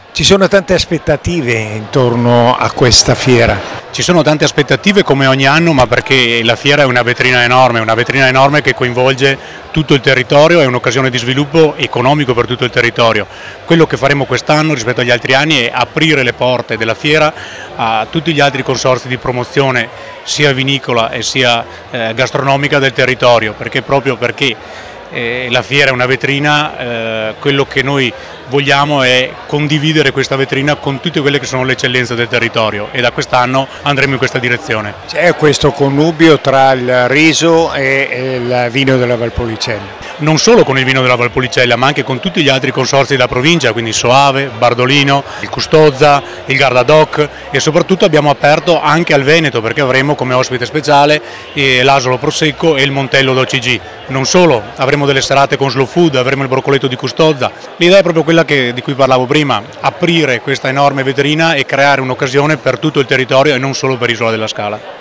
all’evento inaugurale: